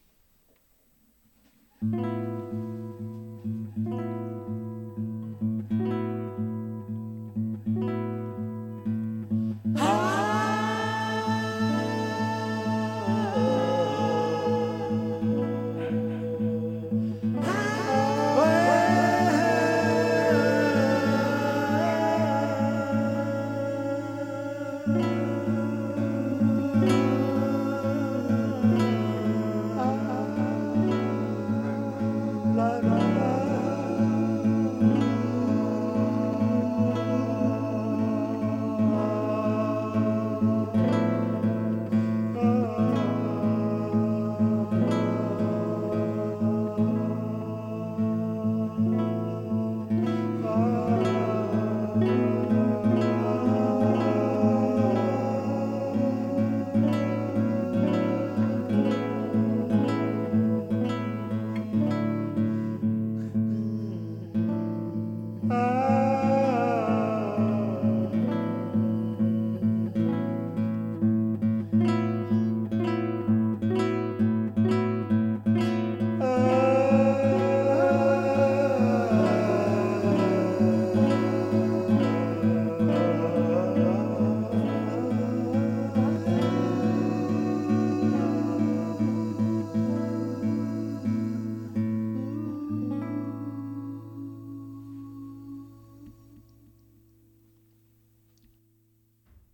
in the upper Douro Valley of Portugal
It is for guitar and voices.